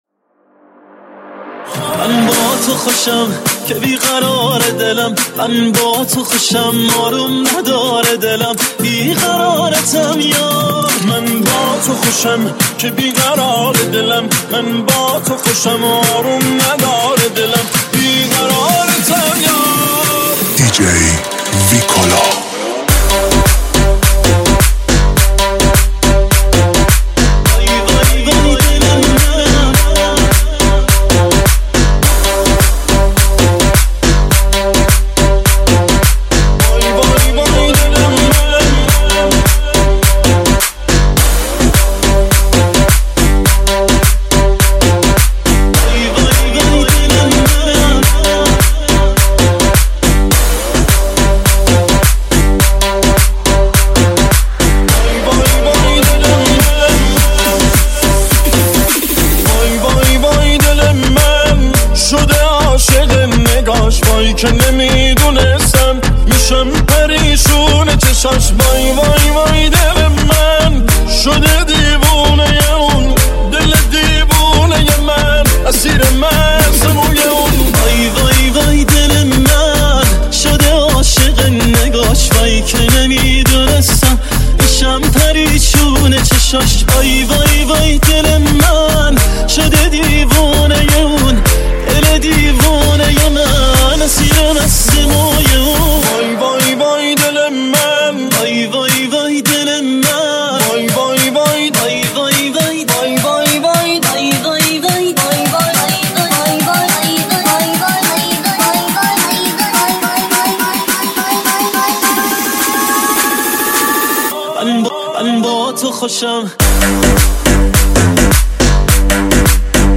آهنگ شاد ارکستی
آهنگ شاد رقصی